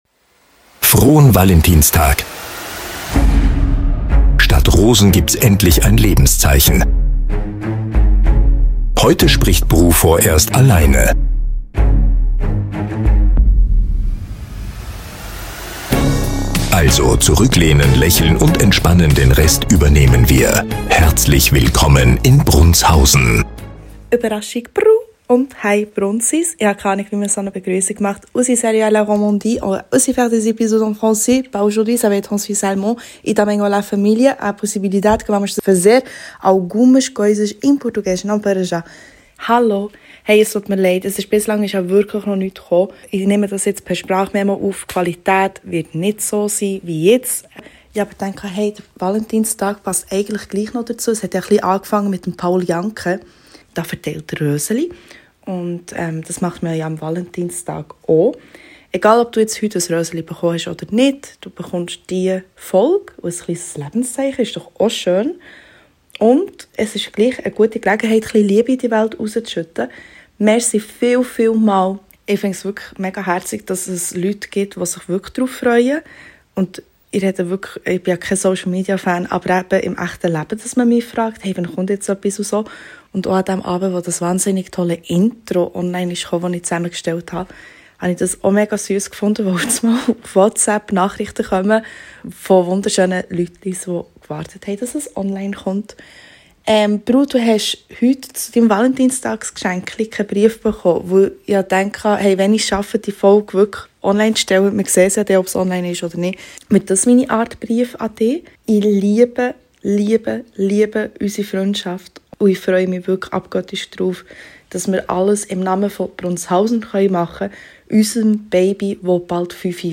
Comedy
Hallöööchen, zwar mit schlechter Quali u extrem spontan: Happy